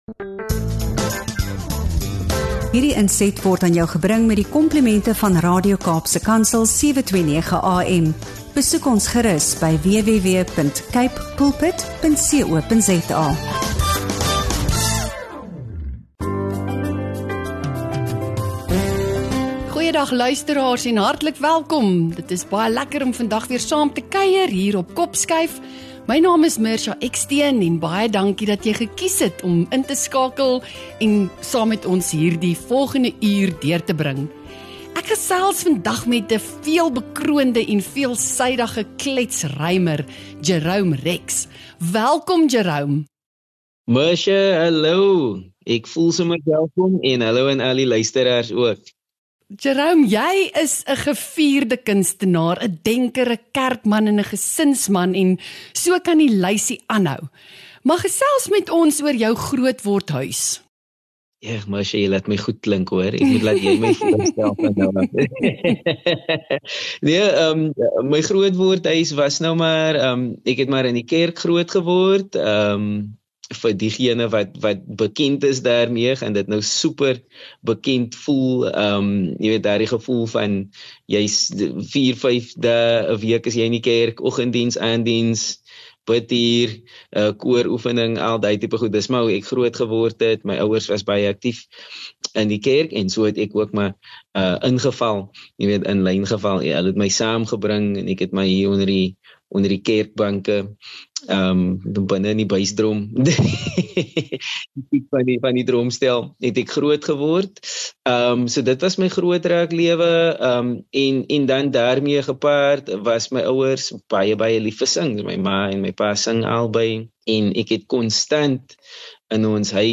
In hierdie episode gesels hy openhartig oor sy musiekloopbaan, toneelspel, sy rol as pa en die stories agter die lirieke.